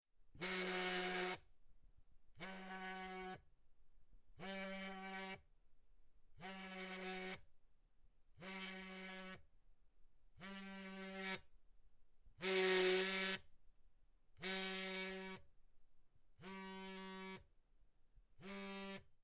Звуки вибрации телефона
Телефон зазвонил в виброрежиме